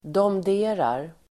Ladda ner uttalet
Uttal: [dåmd'e:rar]